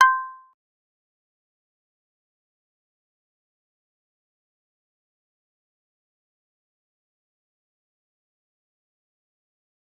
G_Kalimba-C6-pp.wav